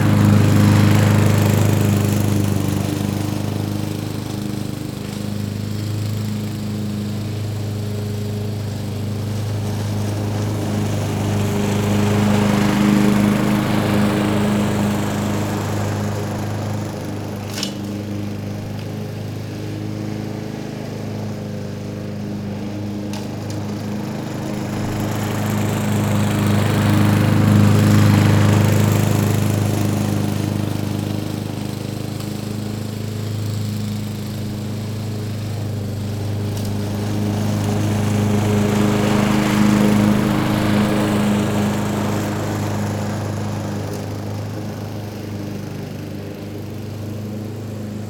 freegardensfx-lawnmower_y4clWSUZ.wav